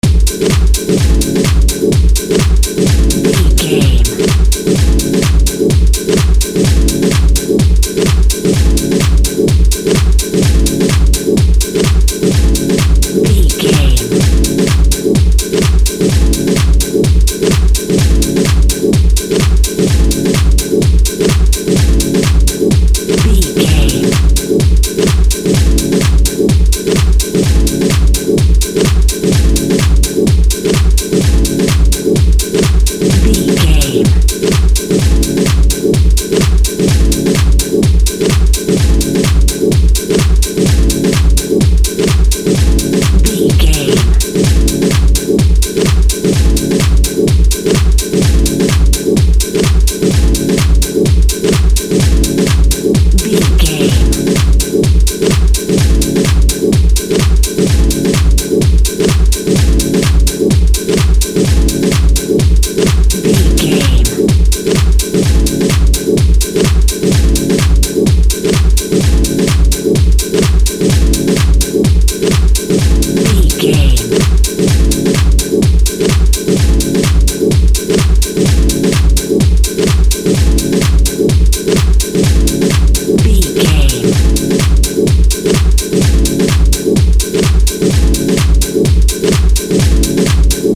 Atonal
hypnotic
industrial
dreamy
smooth
drum machine
synthesiser
house
techno
electro house
synth lead
synth bass